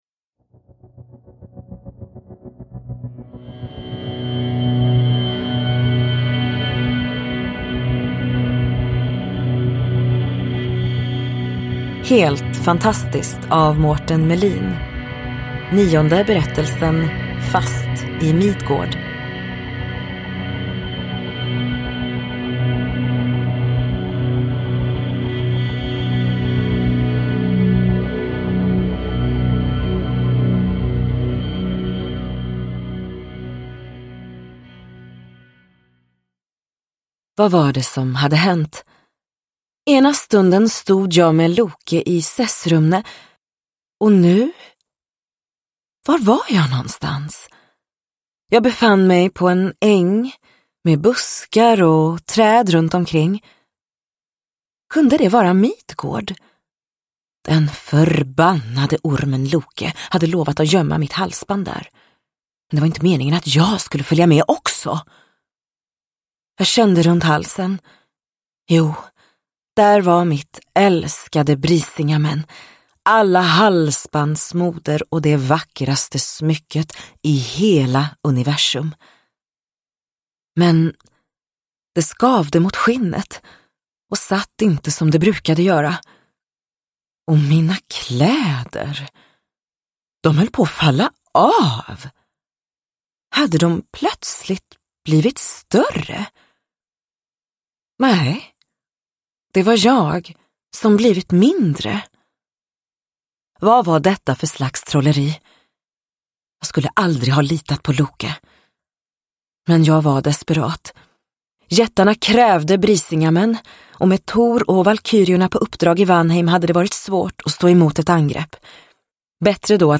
Fast i Midgård : en novell ur samlingen Helt fantastiskt – Ljudbok – Laddas ner